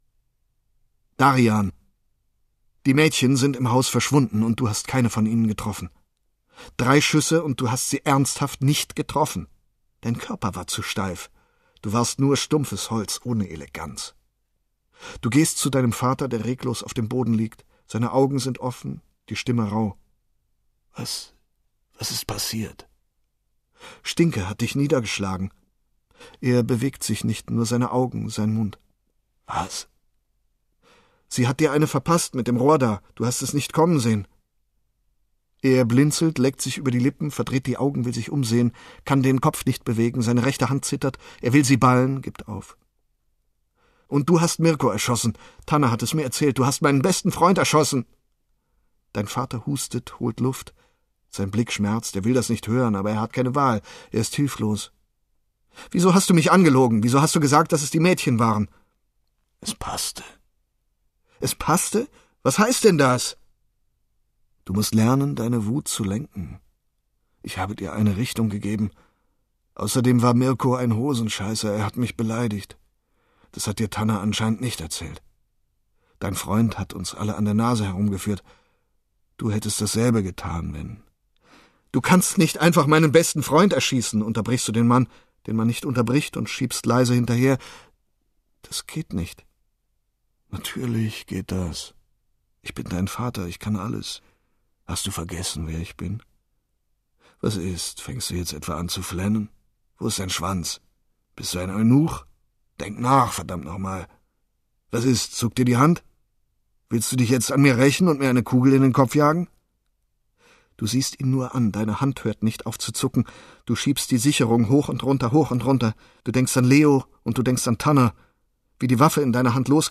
Hörbuch Print
Details zum Hörbuch